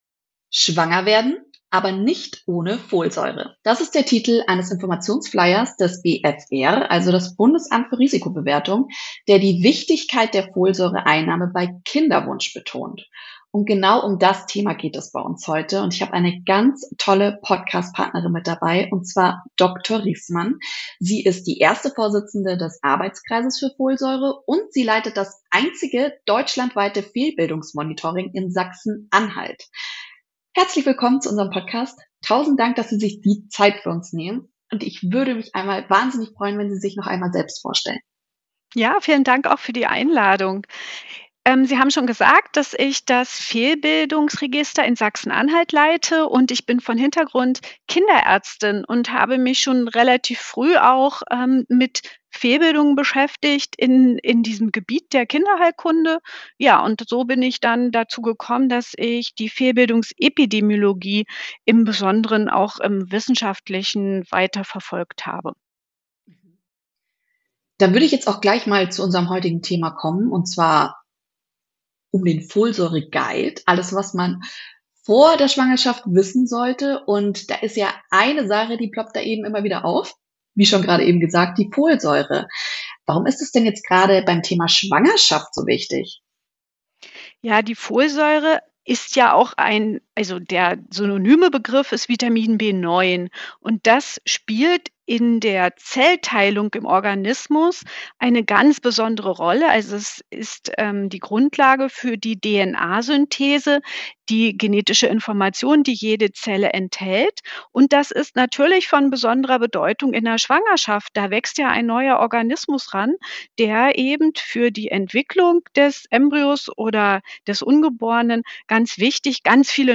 Wir interviewen Expert*Innen rundum Themen aus Gesundheit und Genuss. Wie weit können wir unseren Körper mit unserer Ernährung positiv beeinflussen?